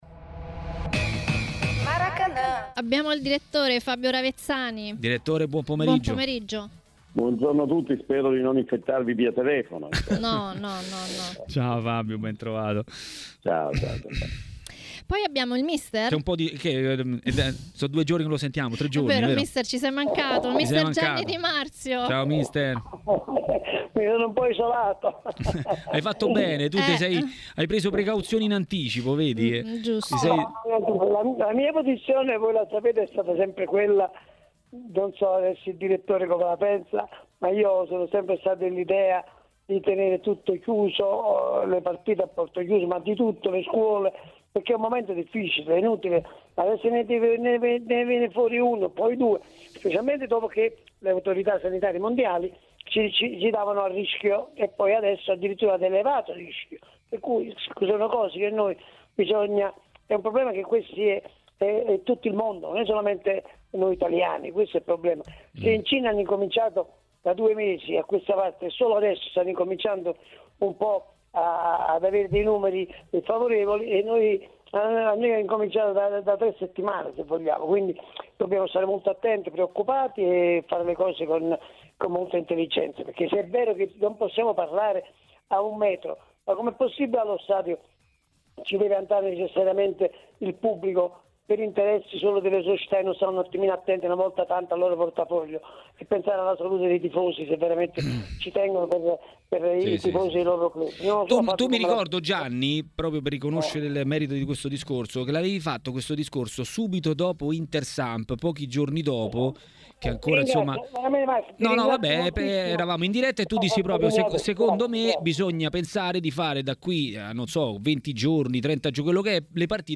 Mister Gianni Di Marzio a Maracanà, nel pomeriggio di TMW Radio, è intervenuto per parlare della questione Coronavirus nello sport italiano.